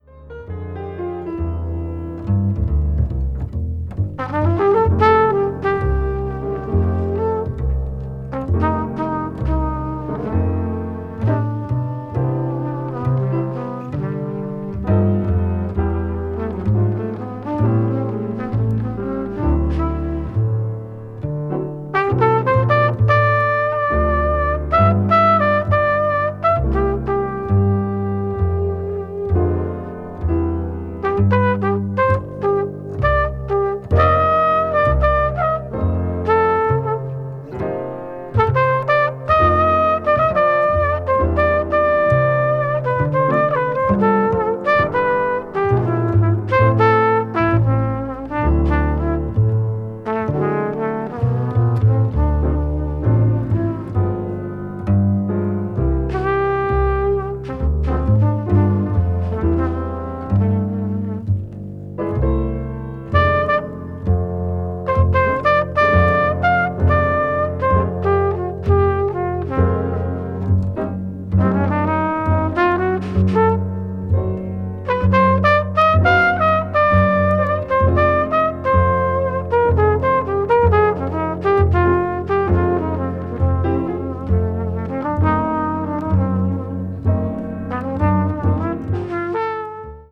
柔らかでシンプルなトランペットの旋律
contemporary jazz   jazz standard   modal jazz   modern jazz